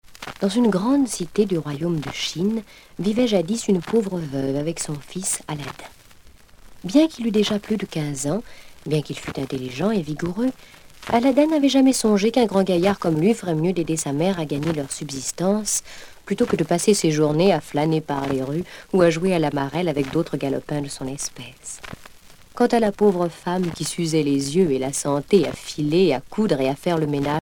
Genre conte